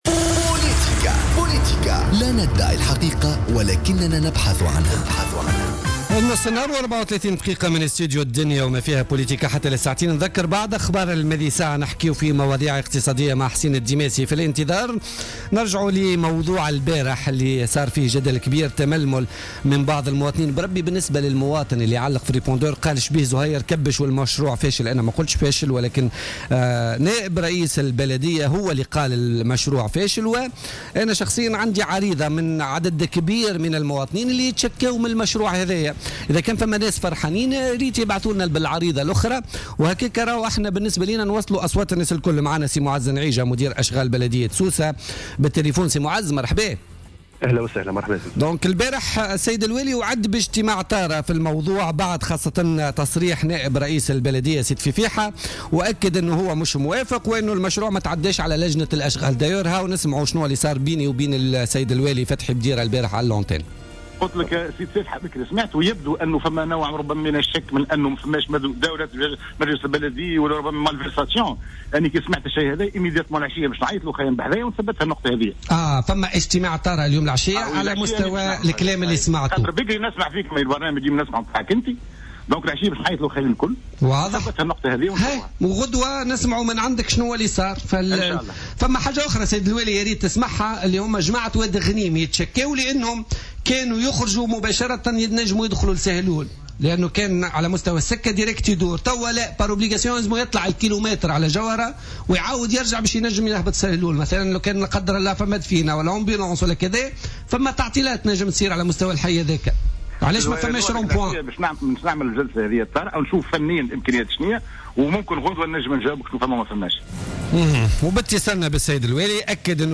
مداخلة له في بوليتيكا